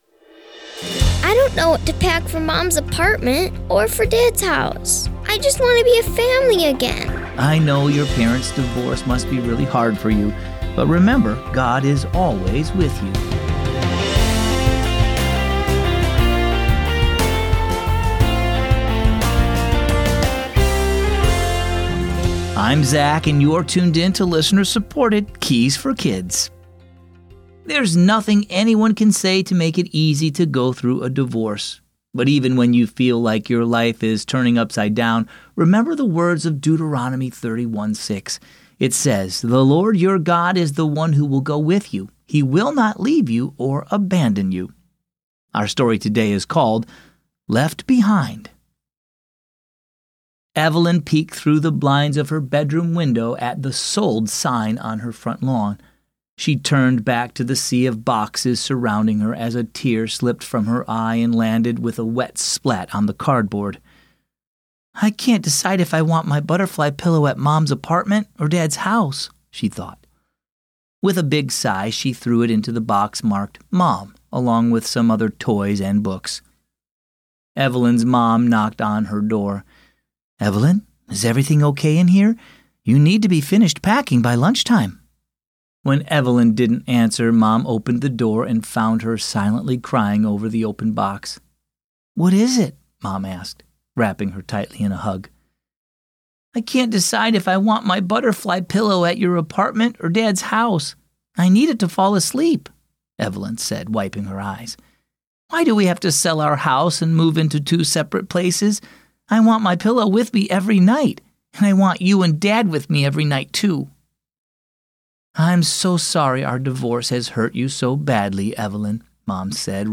Keys for Kids - daily devotions and Bible stories for kids and families